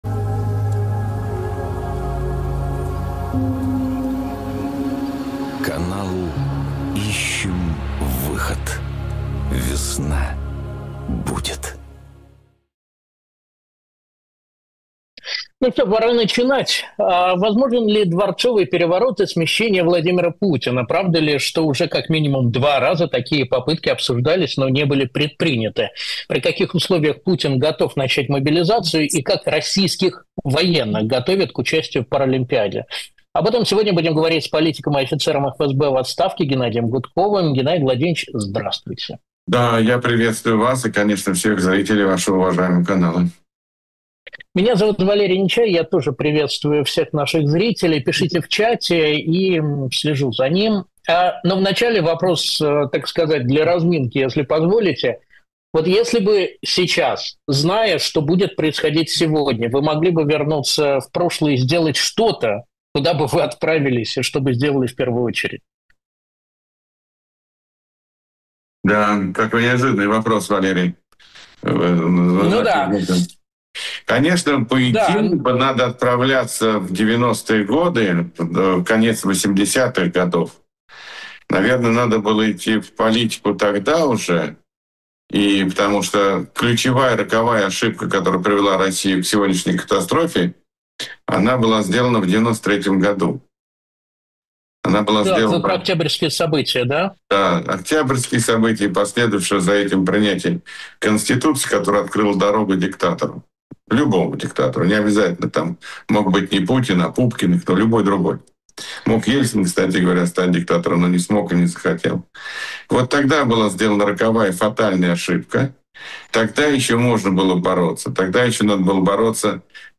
Политик и офицер ФСБ в отставке Геннадий Гудков анализирует устойчивость системы и возможность «дворцового переворота» в современной России. В этом интервью обсуждается, кто на самом деле входит в скрытую оппозицию внутри Кремля и почему страх перед хаотичным развалом страны парализует решения Запада.